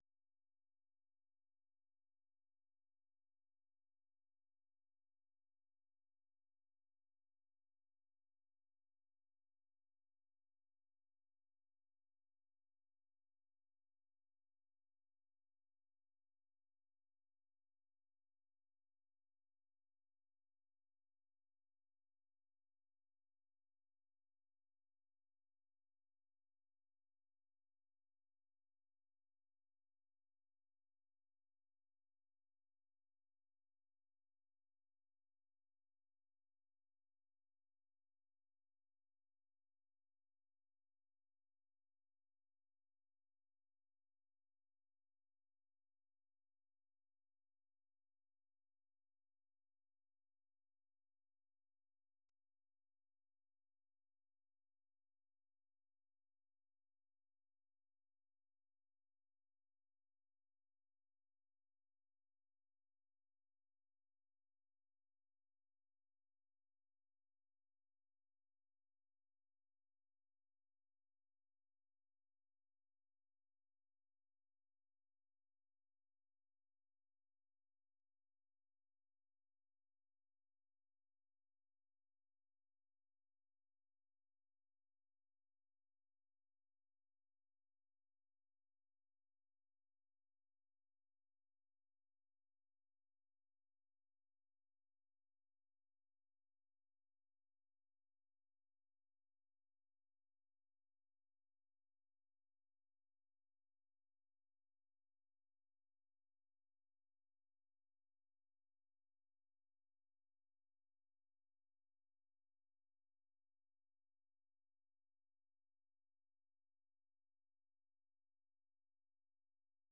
ሰኞ፡-ከምሽቱ ሦስት ሰዓት የአማርኛ ዜና